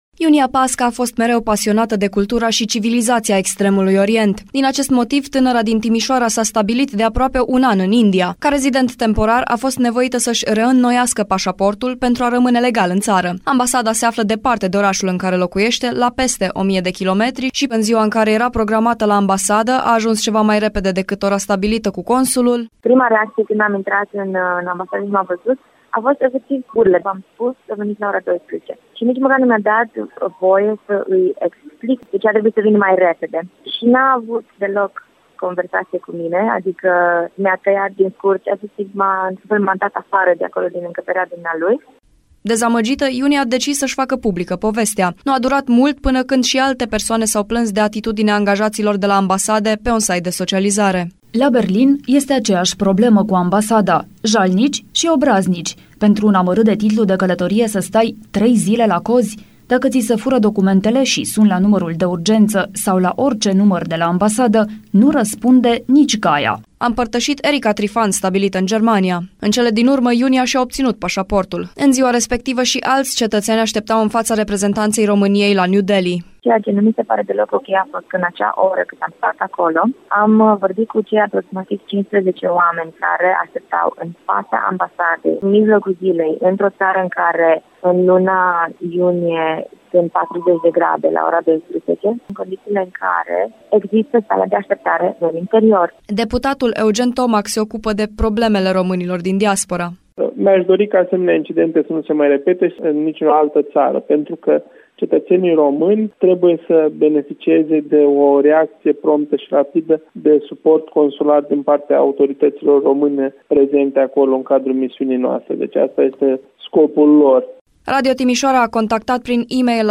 În urma reportajului realizat de Radio Timişoara cu privire la problemele românilor cu ambasadele din străinătate, Ministerul Afacerilor Externe a venit cu un răspuns la solicitarea noastră.